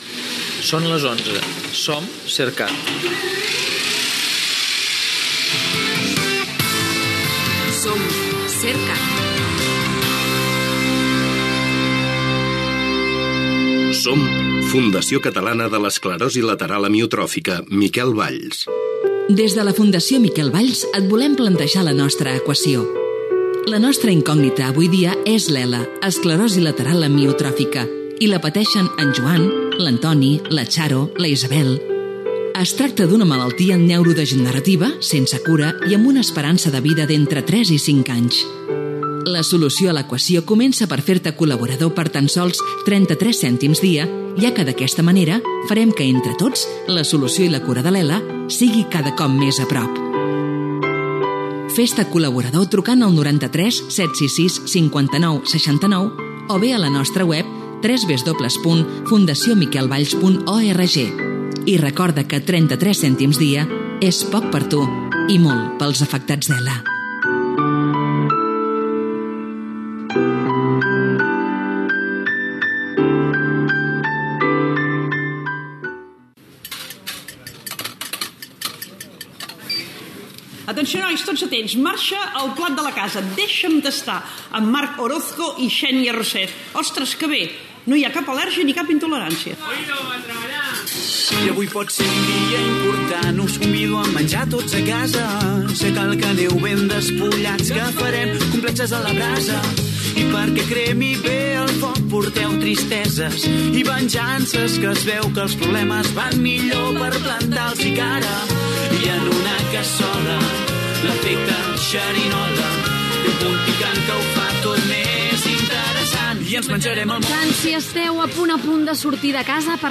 Indicatiu de l'emissora, publicitat, careta del programa (amb la veu de Carme Ruscalleda), presentació, indicatiu, sumari de continguts, indicatiu, entrevista al cuiner Ferran Adrià que explica els seus projectes lligats a la Bulli Foundation i Bulli Lab